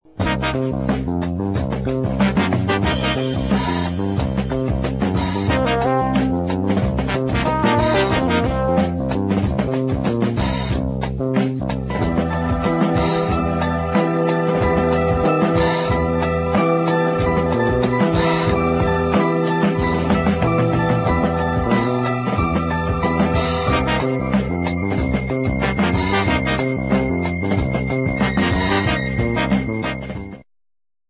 exciting medium instr.